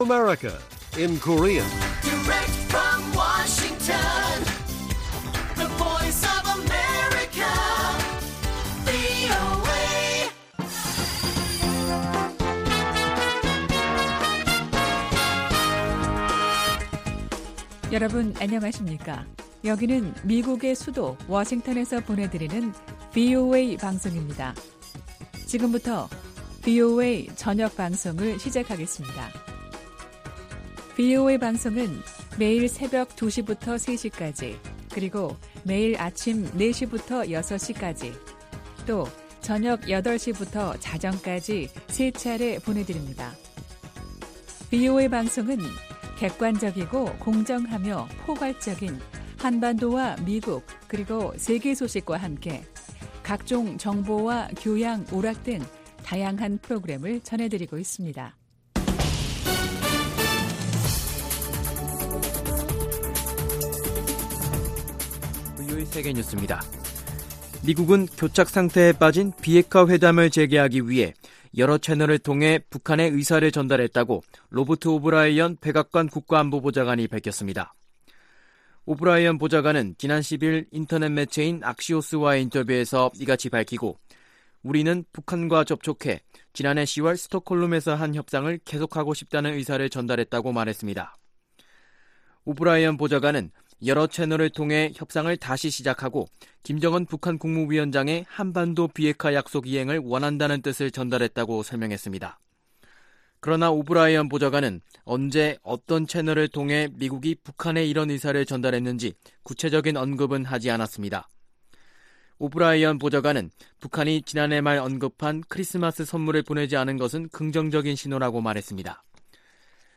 VOA 한국어 간판 뉴스 프로그램 '뉴스 투데이', 2019년 1월 13일1부 방송입니다. 미국은 미-북 협상 재개를 위해 북한 측과 다양한 채널을 통해 접촉하고 있다고 백악관 안보 보좌관이 밝혔습니다. 트럼프 대통령이 김정은 위원장에게 보낸 ‘생일 축하’ 메시지는 북한을 다시 협상 테이블로 이끌려는 시도이지만 돌파구가 마련될 가능성에 대해서는 회의적이라고 전문가들은 분석하고 있습니다.